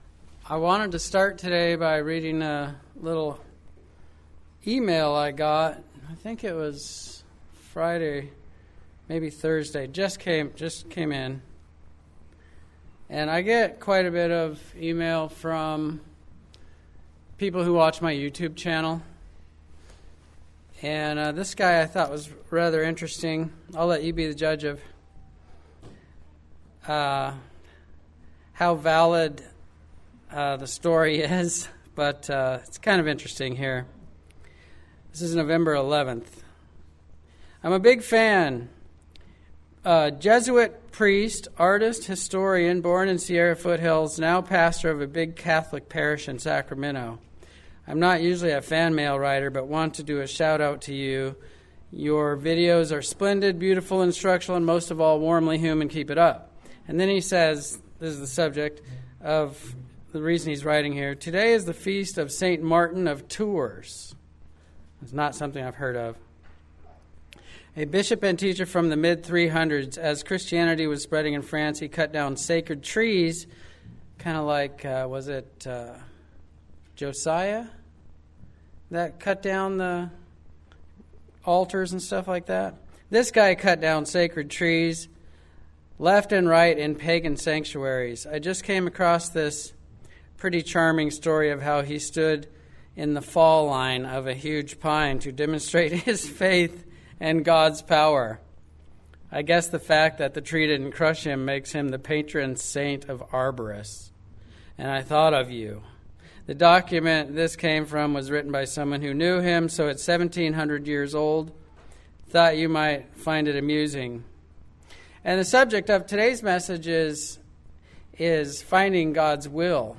Given in Medford, OR Central Oregon